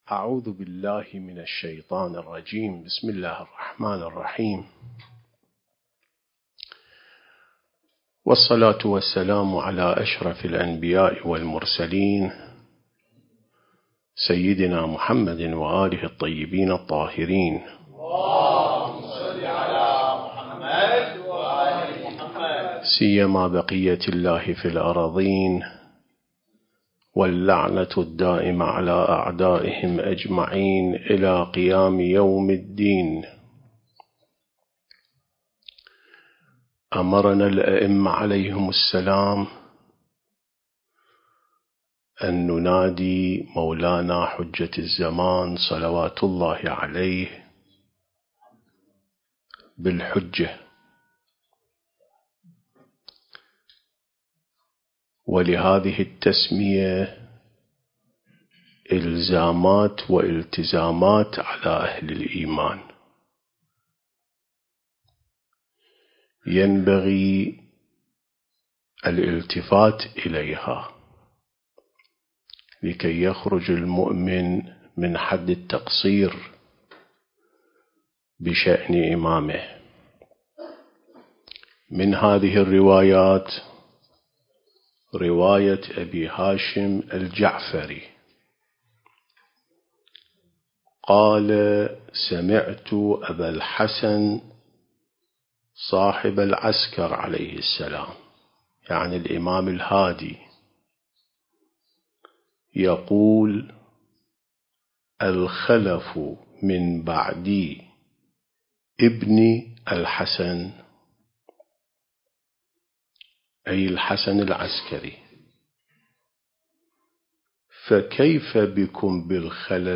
سلسلة محاضرات عين السماء ونهج الأنبياء